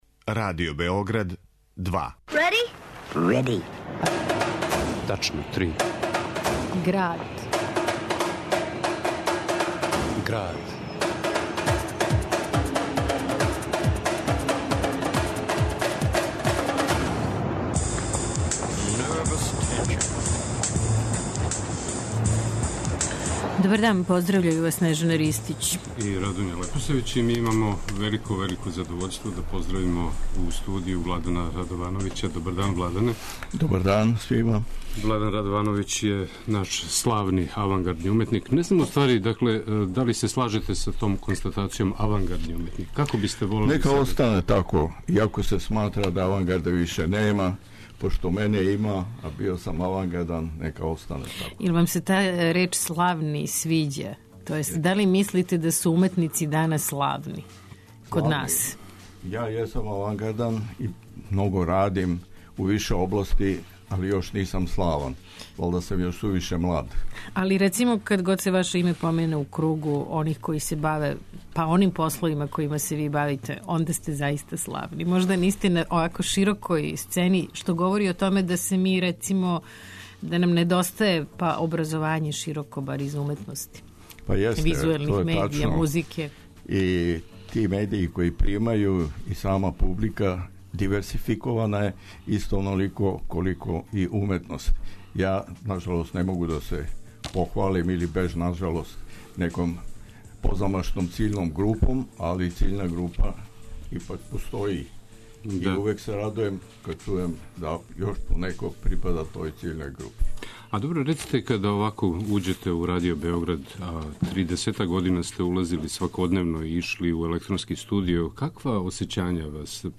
У Граду , гост ће бити Владан Радовановић (1932) - композитор, авангардни уметник, писац... Говориће о својим фото-радовима, о пројектизму, о протоконцептуалној уметности, о уметности уопште...